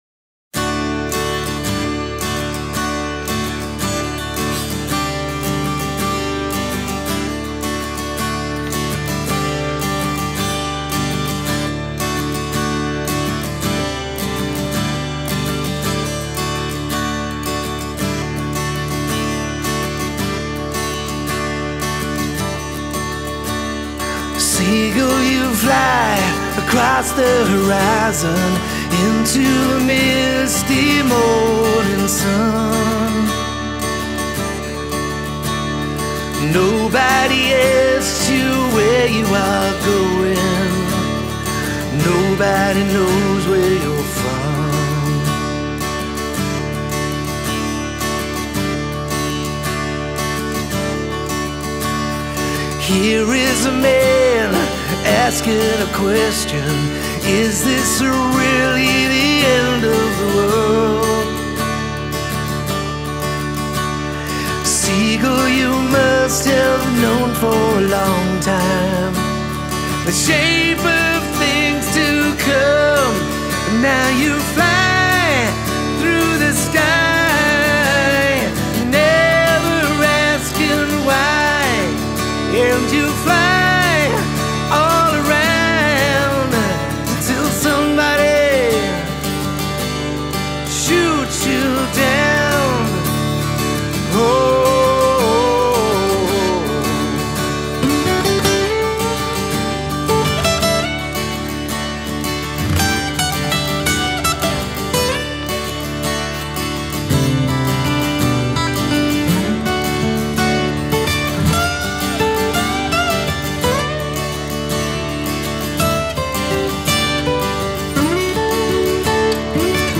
This version feels lived in not repackaged.